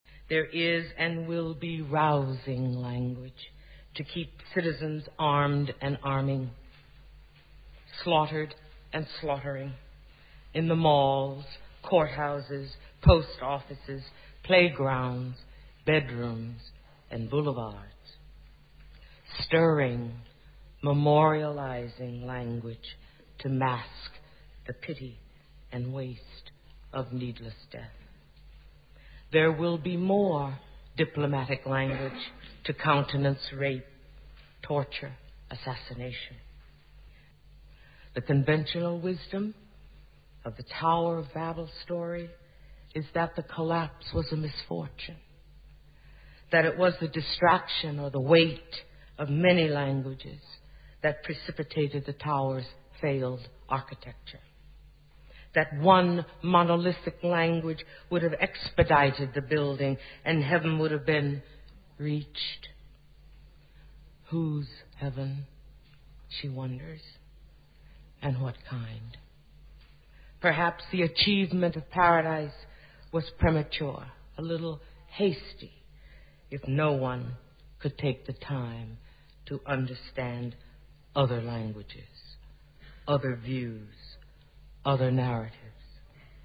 Tags: Travel Asyndeton Figure of Speech Brachylogia Speeches